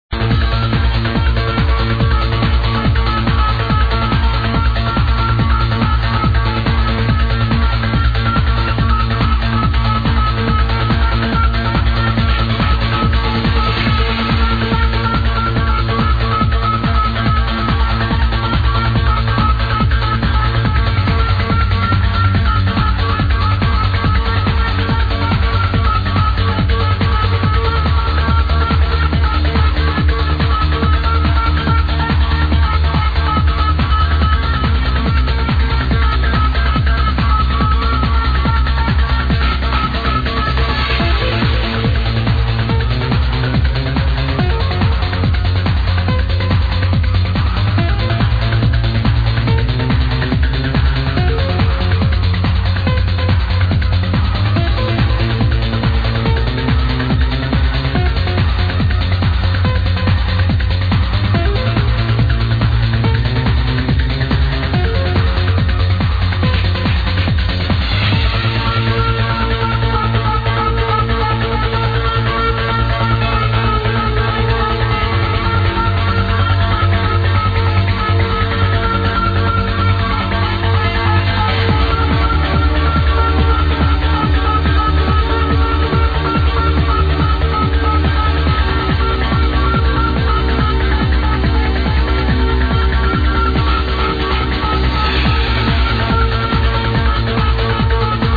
U have a point!!! with very high BPM!